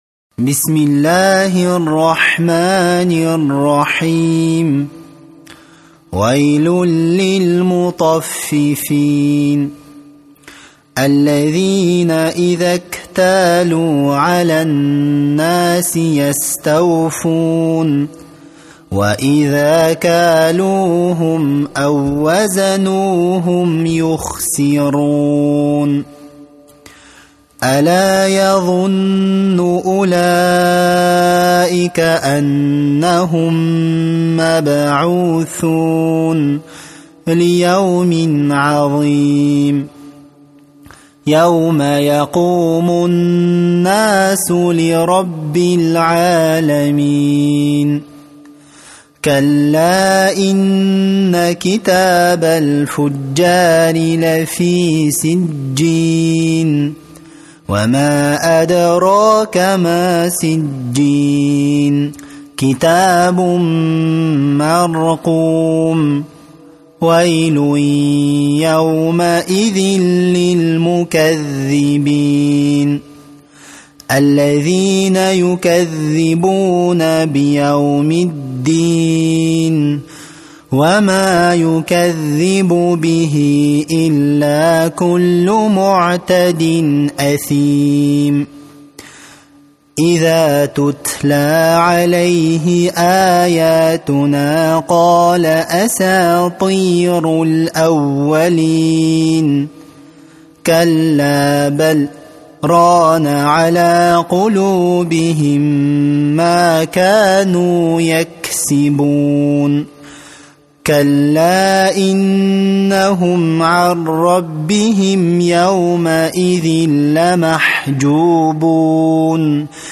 Ассаляму алейкум уа рахматуЛлахи уа баракатух! маша Аллах, ариптери оте анык естиледи екен, жаттаганга оте ынгайлы))) Осы кисинин кырагатымен дугаларды да алсак нур устине нур болар еди, мумкин болар ма екен?